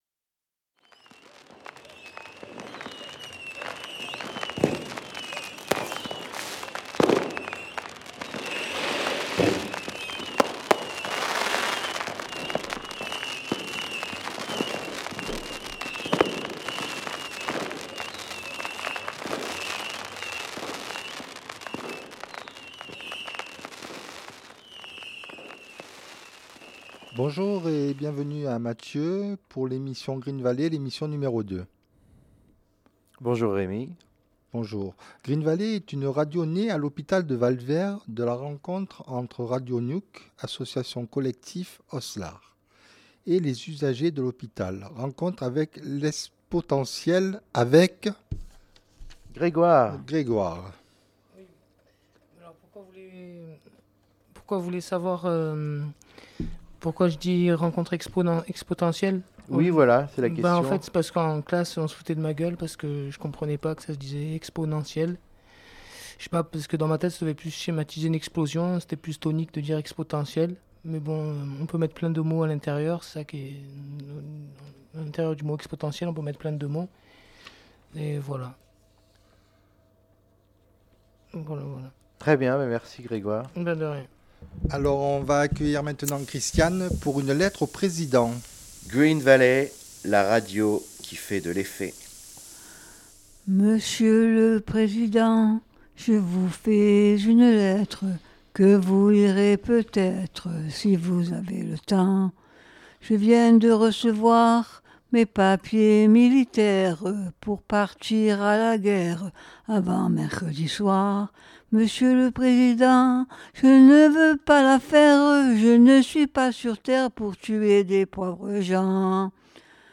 live_green_valley_6_mai_2025.mp3